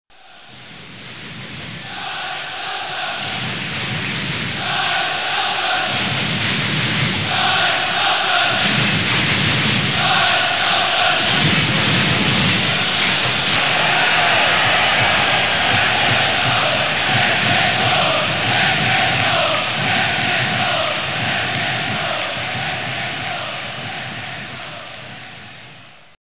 betze_roar.mp3